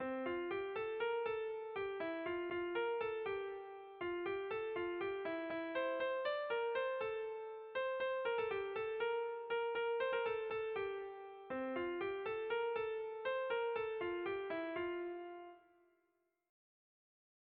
Seiko txikia (hg) / Hiru puntuko txikia (ip)
A-B-C-A